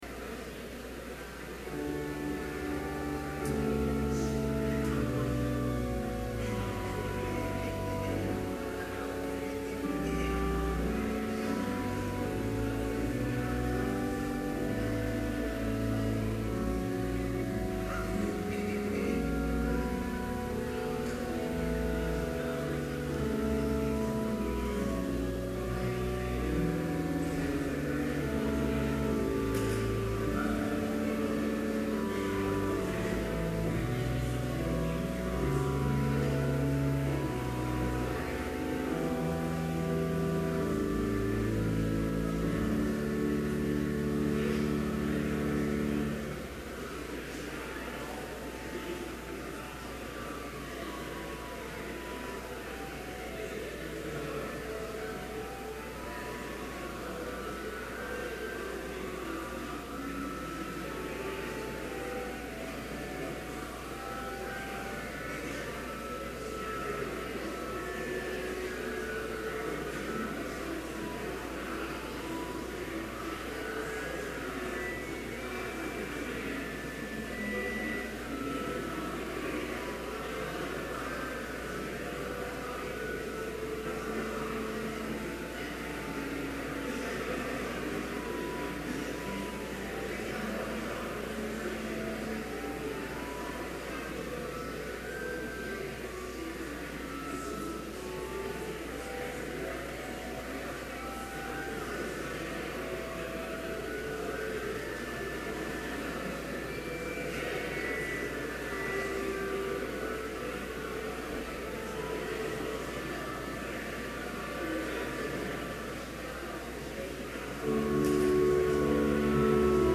Complete service audio for Chapel - September 14, 2011
COMMEMORATING THE 100th ANNIVERSARY OF OLD MAIN Prelude Hymn 8, vv. 1-3, Christ Is Made the Sure Foundation Reading: I Corinthians 3:10-11 Homily Prayer (responsively)